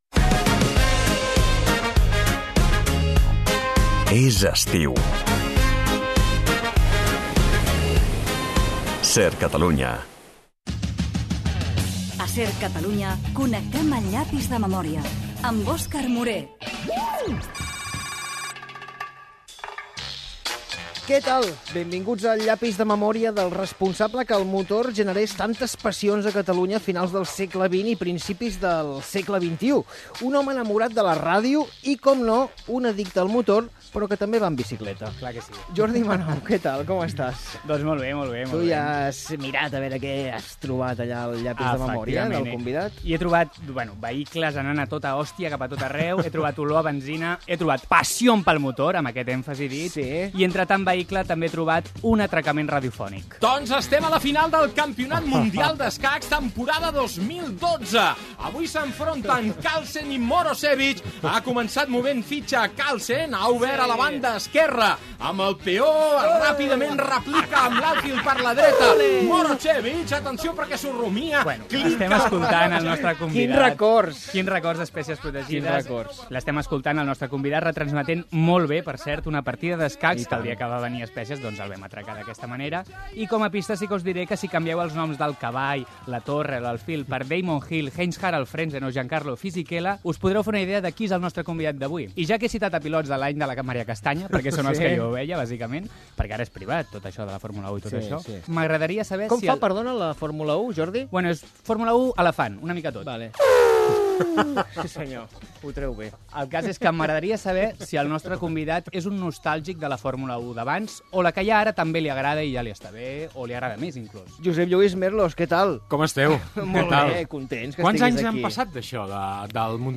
Indicatiu d'estiu de l'emissora, indicatiu del programa
entrevista sobre la seva trajectòria a la ràdio